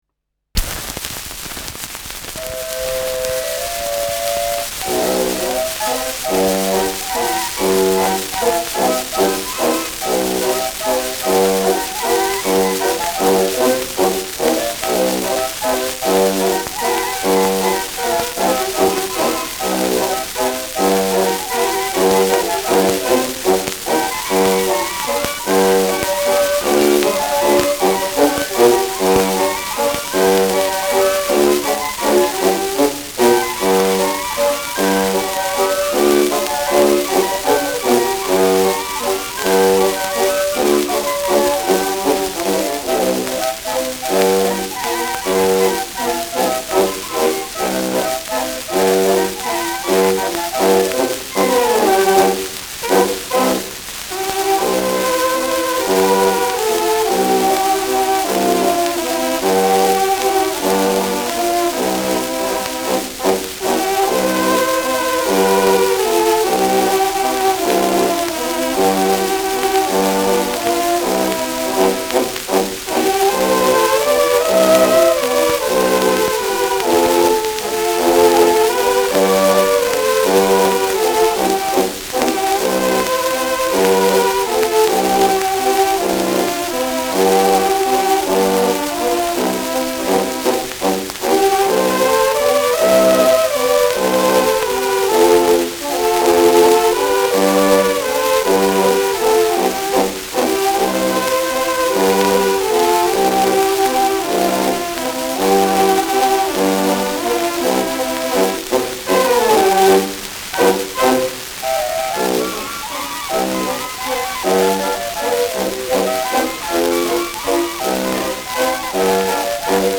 Schellackplatte
präsentes bis starkes Rauschen : präsentes Knistern : stark abgespielt : leiert
Weana Ländler-Kapelle (Interpretation)
[Wien] (Aufnahmeort)